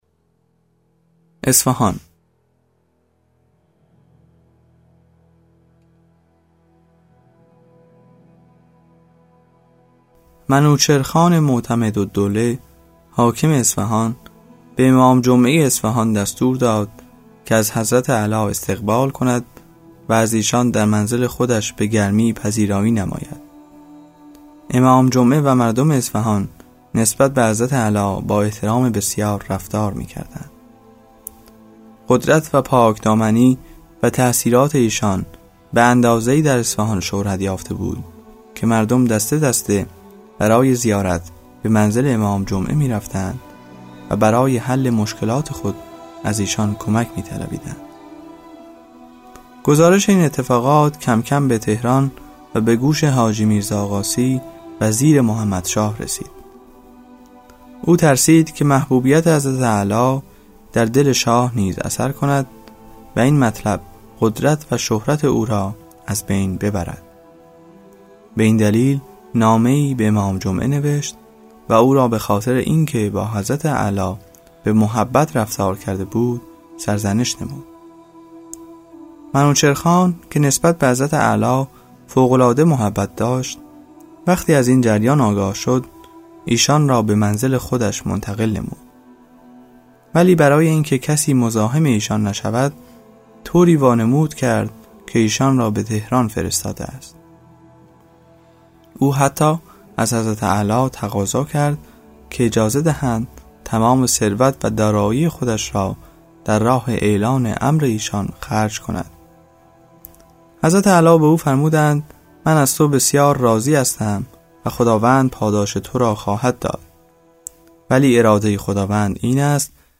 کتاب صوتی سالهای سبز | تعالیم و عقاید آئین بهائی